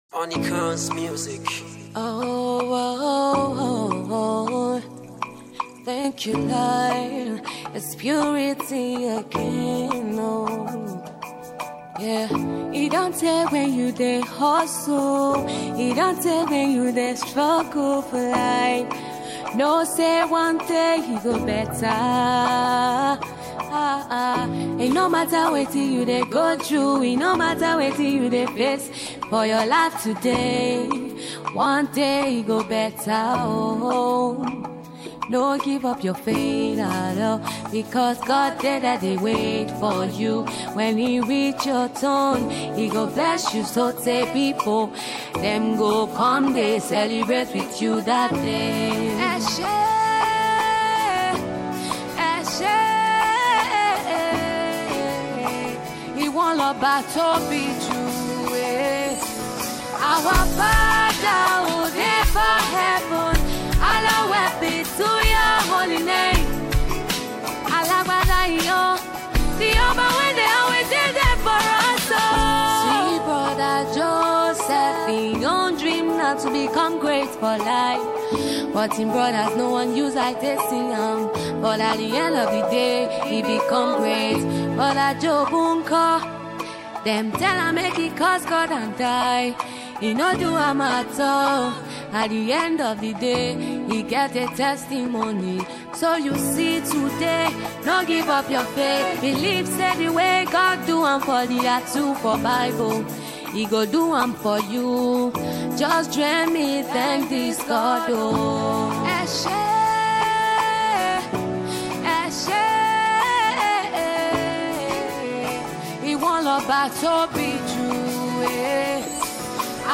Emerging Gospel artist
inspirational single